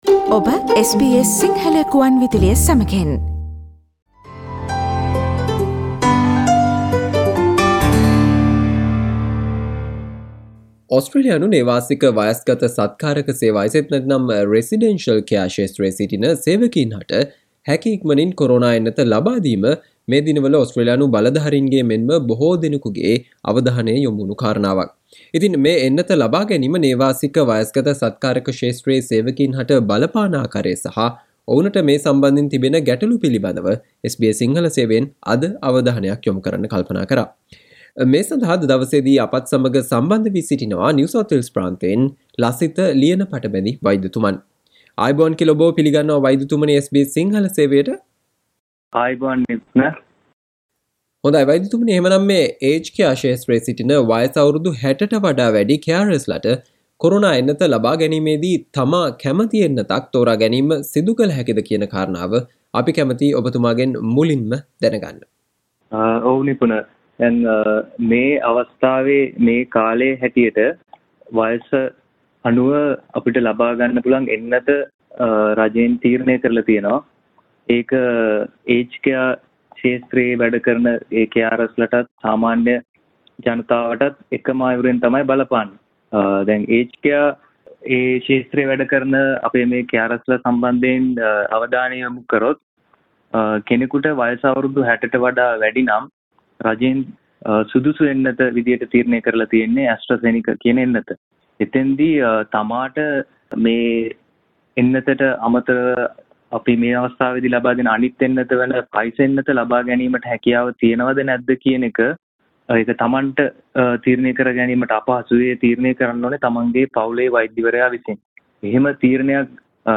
SBS සිංහල සේවය සිදු කල සාකච්චාව